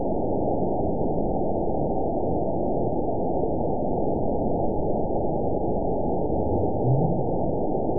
event 912341 date 03/24/22 time 22:27:35 GMT (3 years, 1 month ago) score 9.57 location TSS-AB01 detected by nrw target species NRW annotations +NRW Spectrogram: Frequency (kHz) vs. Time (s) audio not available .wav